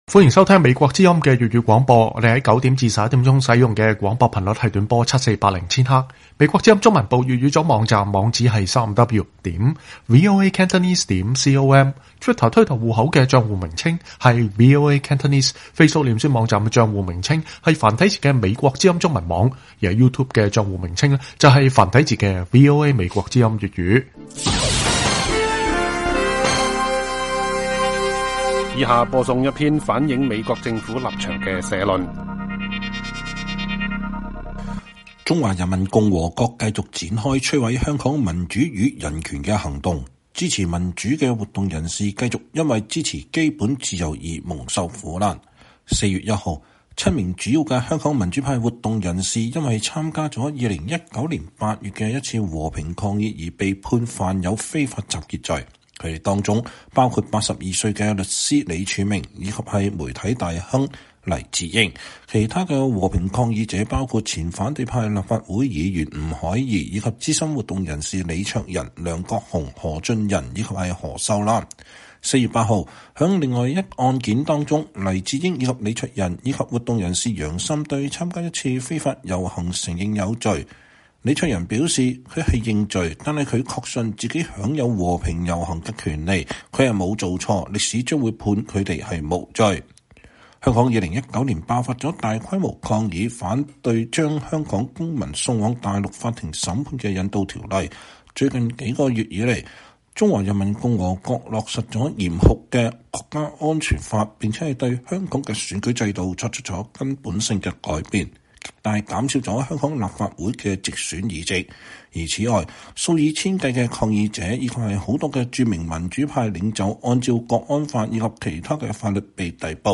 美國政府立場社論